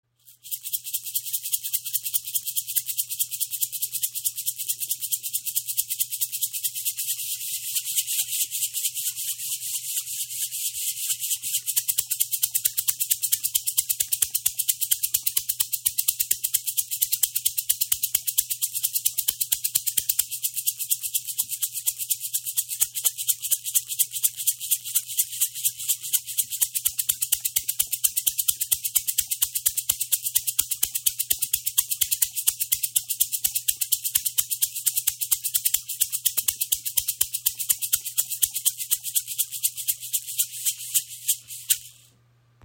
Shipibo Schamanen Rassel „Heilerschlange“ aus Peru
• Icon Kraftvoller Klang für Rituale und spirituelle Arbeit
Ihr Klang trägt eine reinigende, erdende Schwingung – ideal für zeremonielle Klangarbeit, energetische Reinigung oder stille Innenschau.